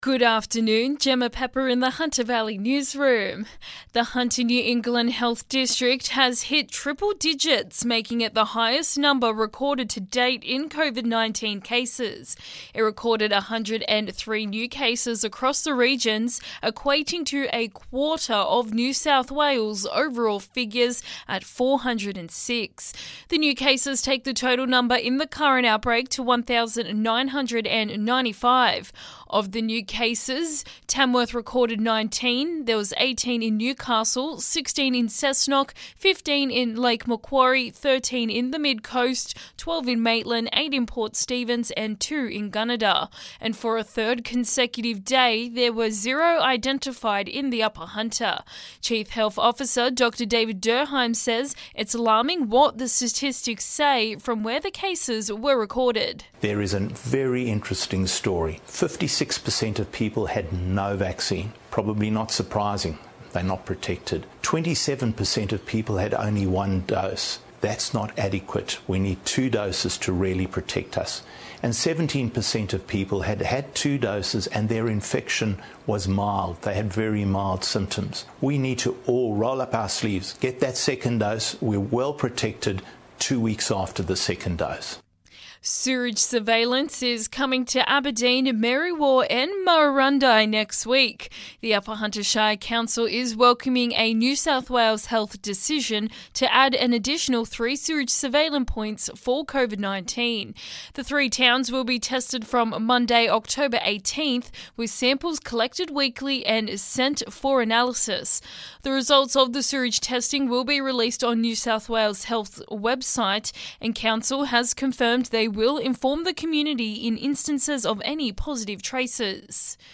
Listen: Hunter Local News Headlines 14/10/2021